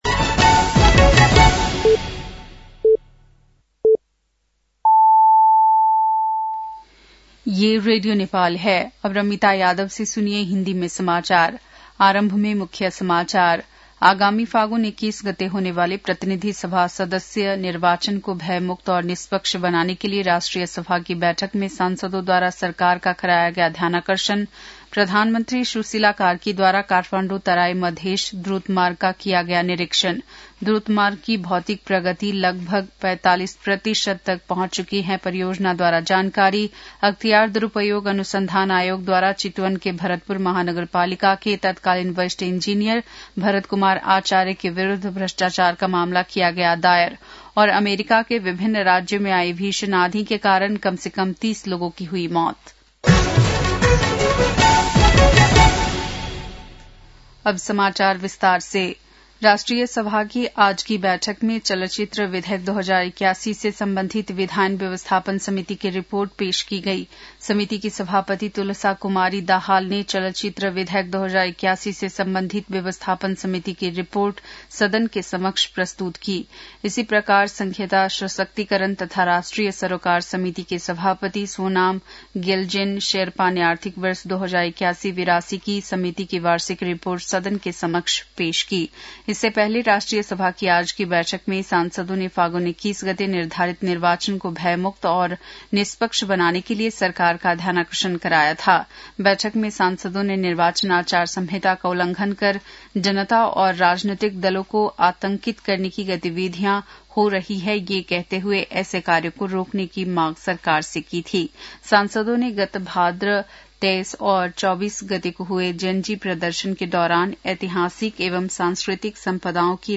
बेलुकी १० बजेको हिन्दी समाचार : १३ माघ , २०८२